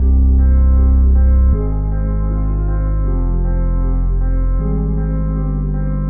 描述：用omnisphere制作
Tag: 157 bpm Trap Loops Piano Loops 1.03 MB wav Key : Unknown